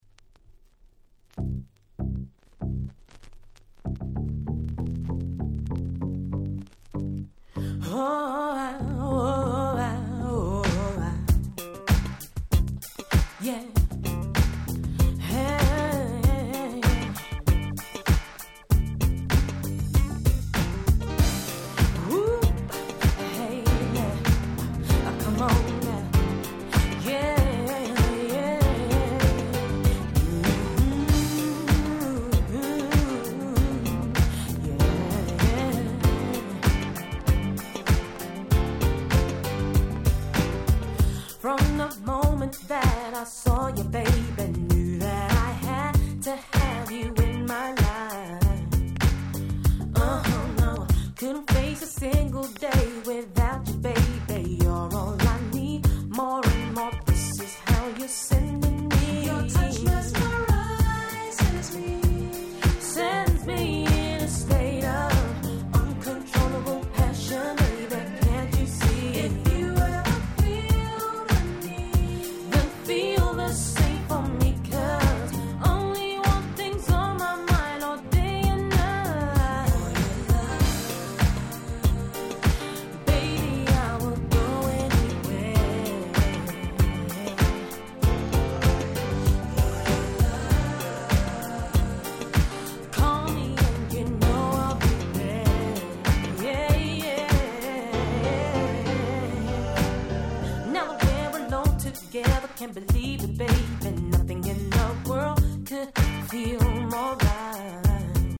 頭から尻尾の先までNiceなUK Soulがてんこ盛り！